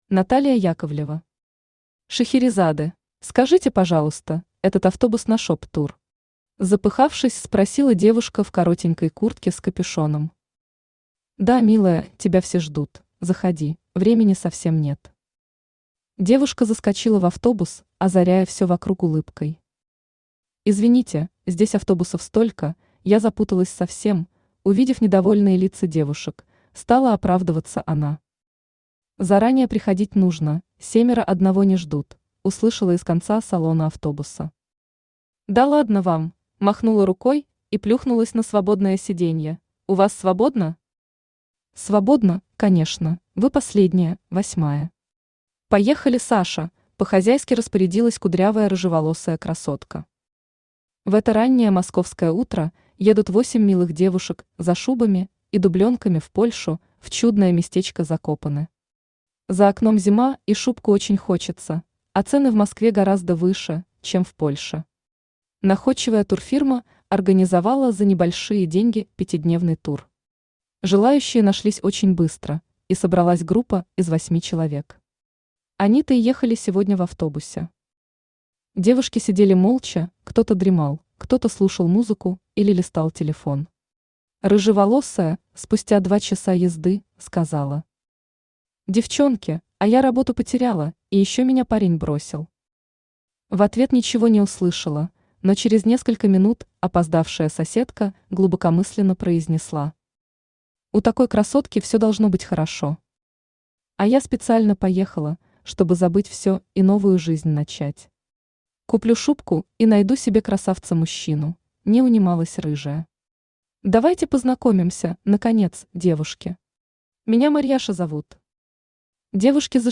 Aудиокнига Шахерезады Автор Наталия Яковлева Читает аудиокнигу Авточтец ЛитРес.